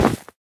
Minecraft Version Minecraft Version 25w18a Latest Release | Latest Snapshot 25w18a / assets / minecraft / sounds / entity / snowman / hurt1.ogg Compare With Compare With Latest Release | Latest Snapshot
hurt1.ogg